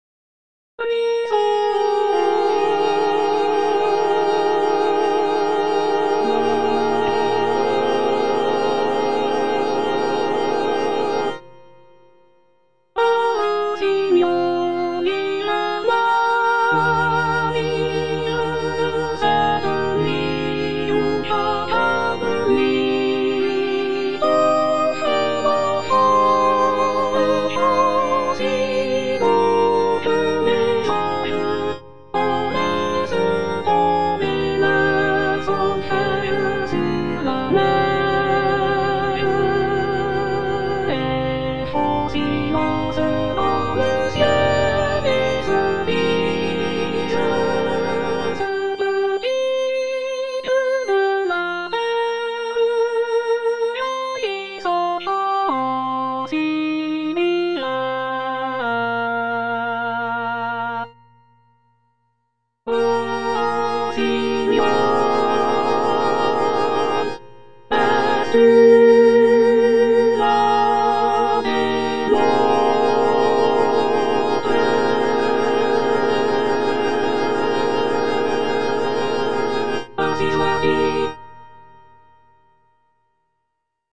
Mezzosoprano/Soprano I (Emphasised voice and other voices)